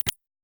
unlock.opus